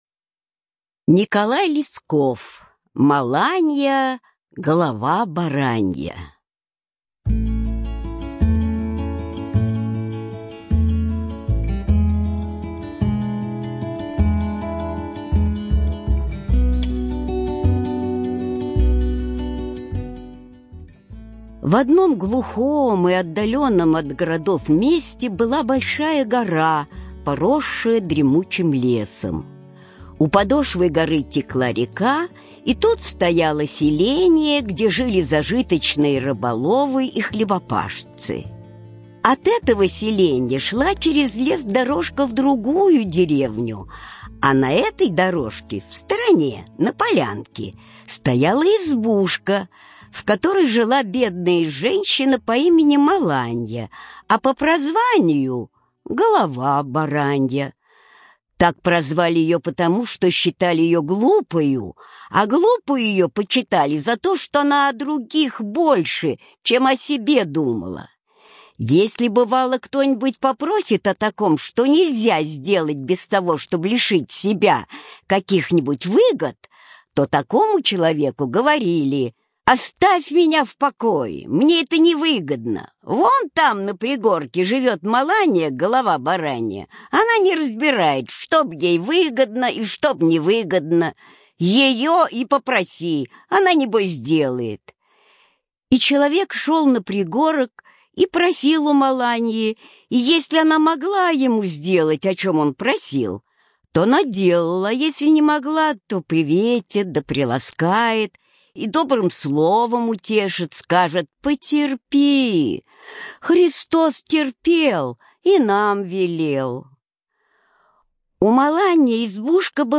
Маланья - голова баранья - аудиосказка Лескова - слушать онлайн